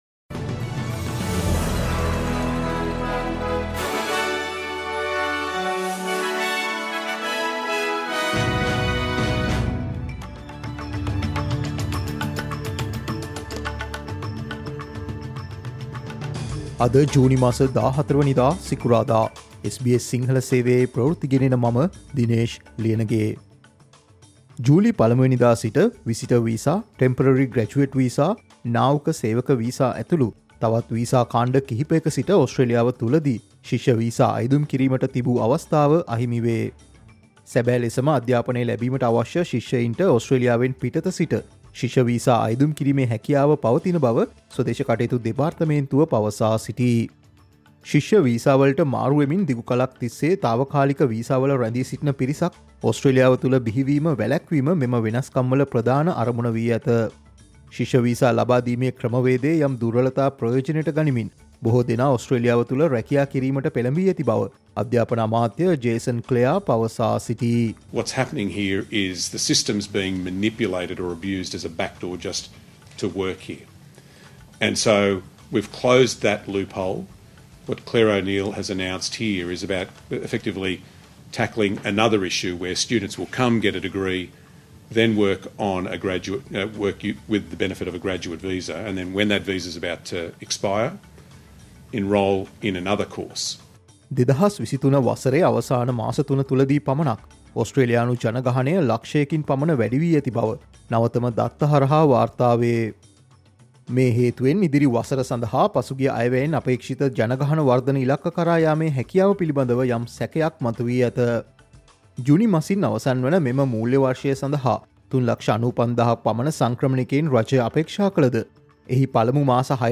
Australia's population increased by 100,000 in the last quarter of 2023 alone: News Flash June 14
Australia's news in Sinhala, Listen, SBS Sinhala News Flash today